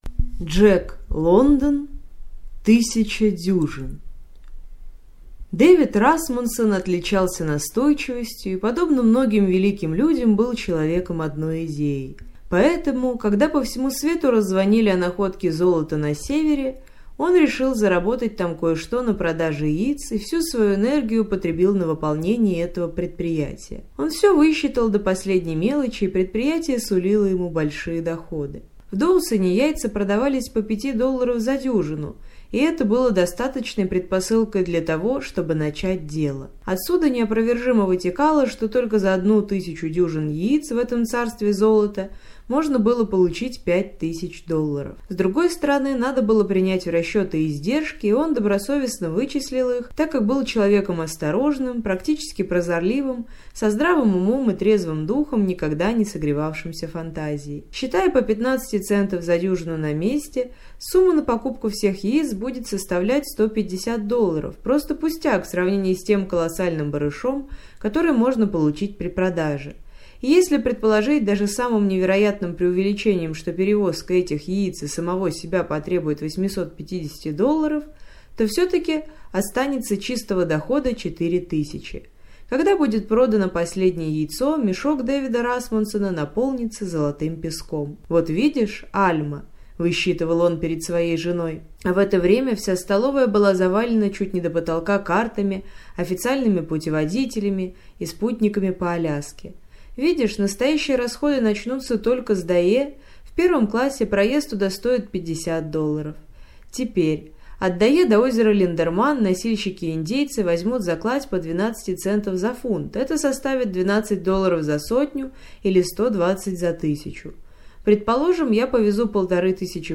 Аудиокнига Тысяча дюжин | Библиотека аудиокниг